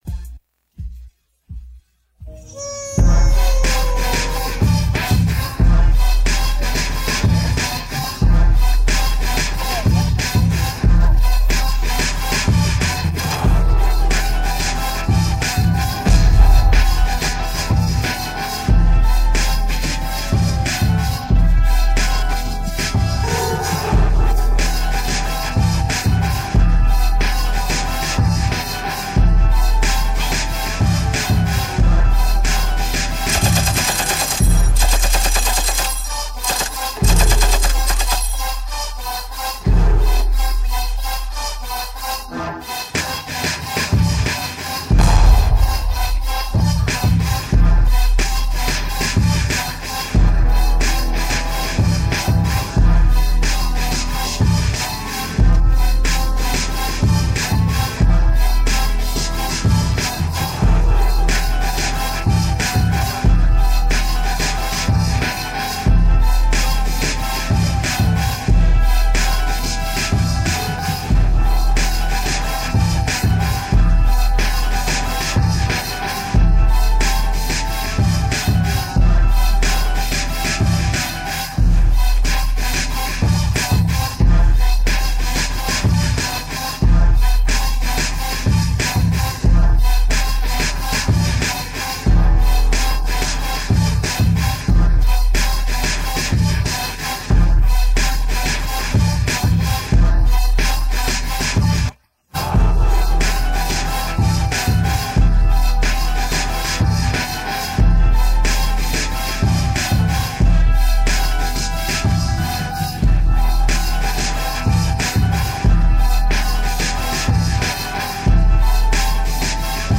Download Posted in Instrumentals .